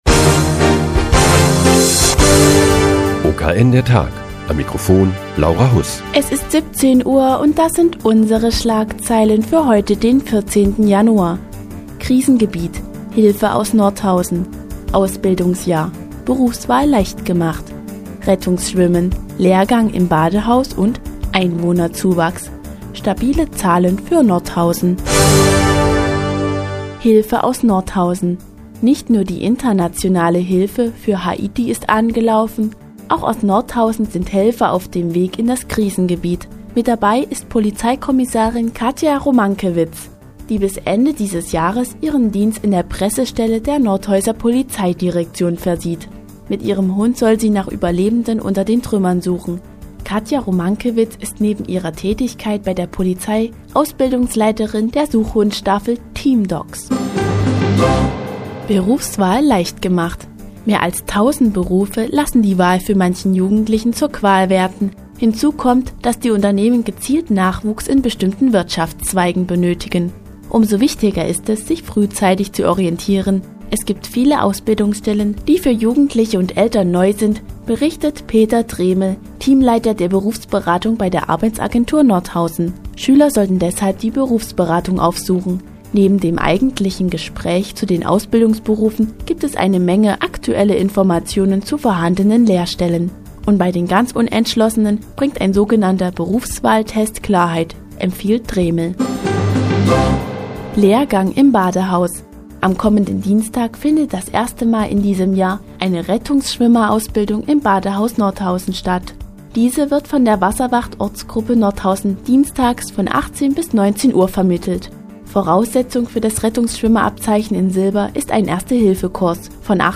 Die tägliche Nachrichtensendung des OKN ist nun auch in der nnz zu hören. Heute geht es um einen Rettungsschwimmerlehrgang im Badehaus und den Einwohnerzuwachs in Nordhausen.